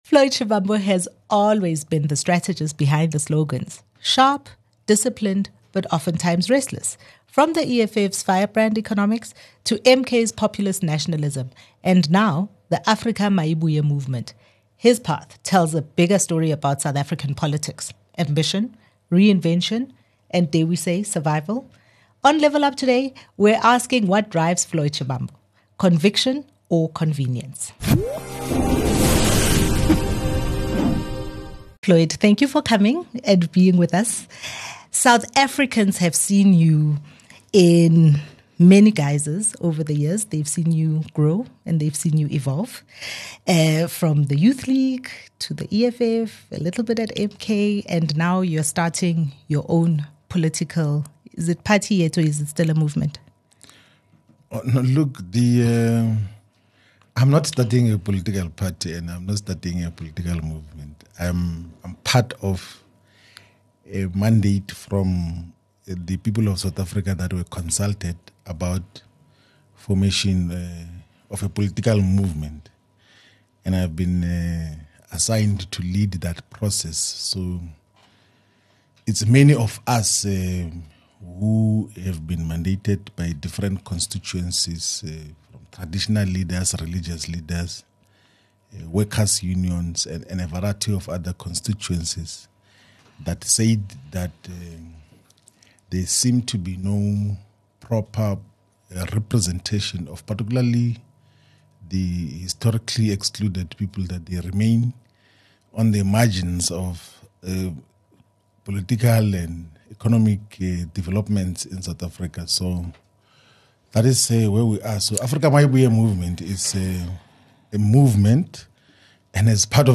Floyd speaks candidly about ideological consistency, political disillusionment, organisational decay, and why he believes South Africa still needs a true revolutionary movement.